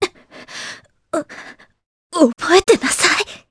Epis-Vox_Dead_jp.wav